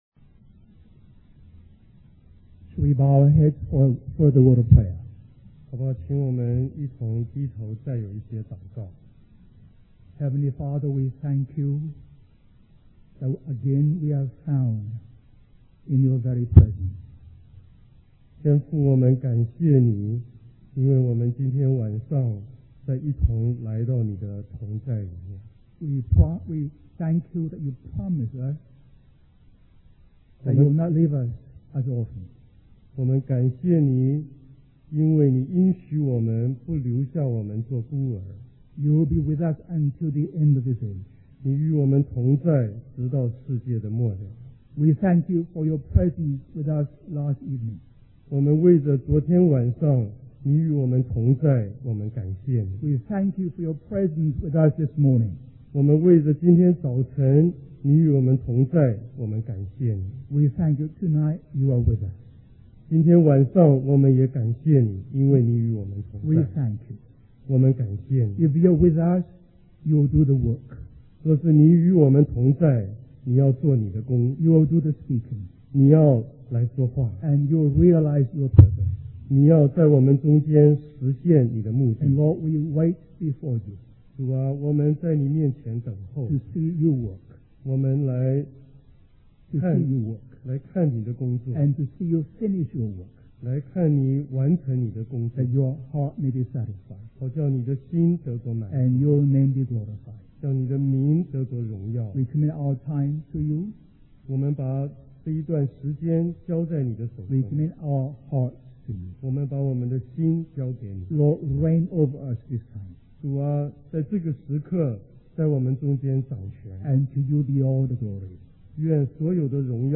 A collection of Christ focused messages published by the Christian Testimony Ministry in Richmond, VA.
West Coast Christian Conference